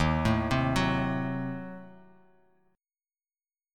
D#dim7 chord